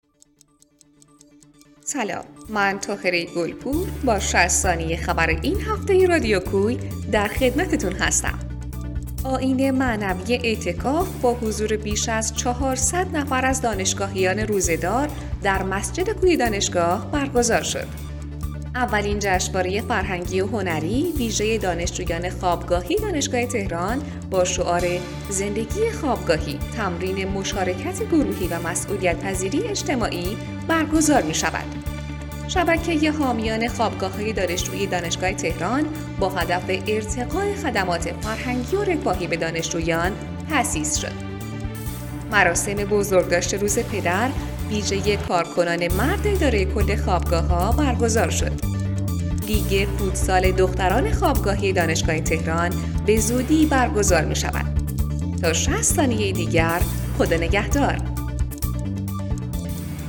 اخبار ۶۰ ثانیه‌ای [۲۶ بهمن ۱۴۰۱]